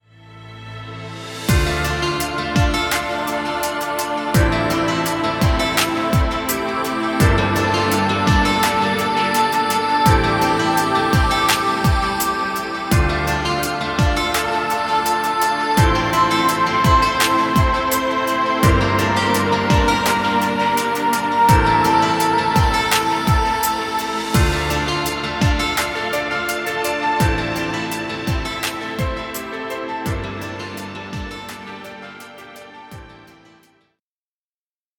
Chillout muisc. Background music Royalty Free.